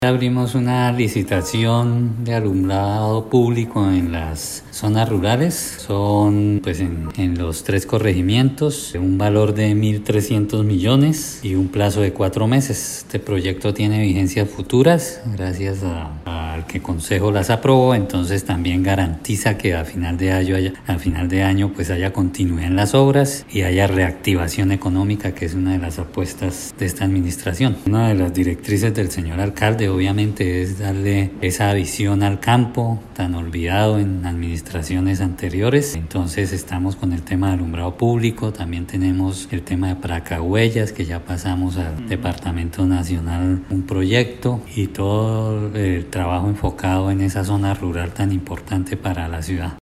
Descargar audio: Iván Vargas, secretario de Infraestructura